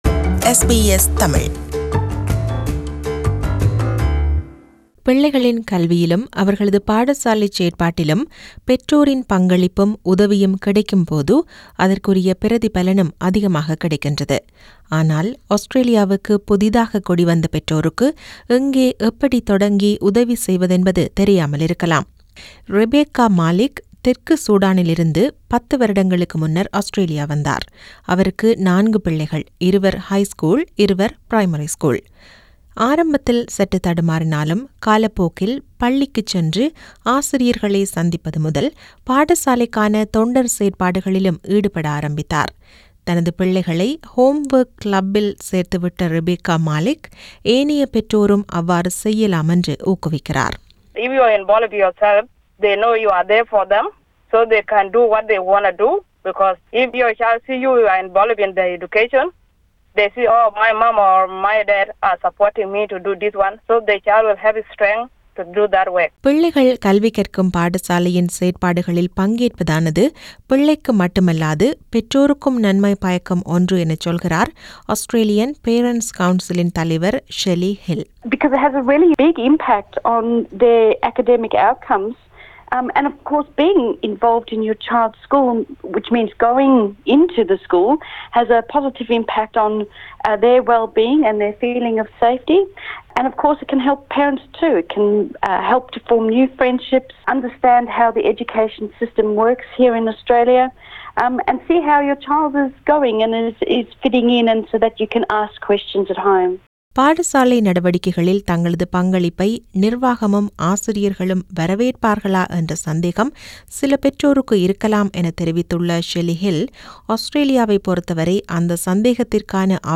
விவரணத்தைத் தமிழில் தருகிறார்